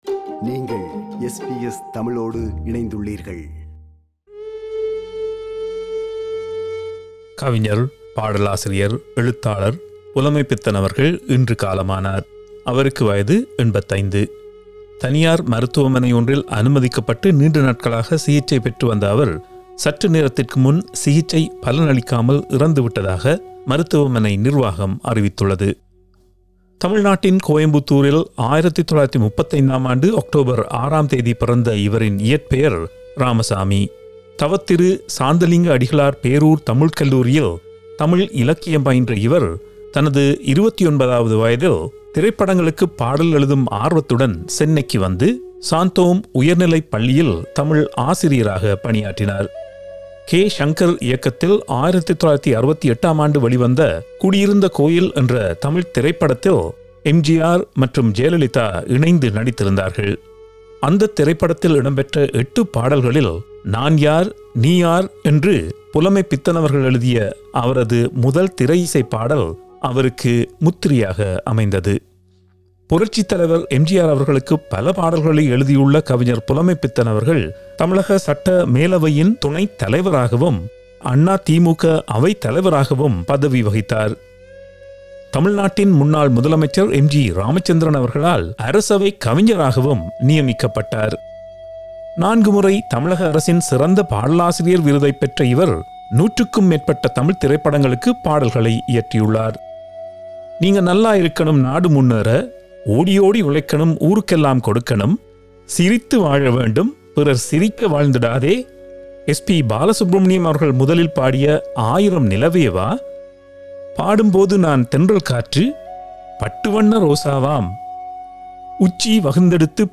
Australian news bulletin for Thursday 09 September 2021.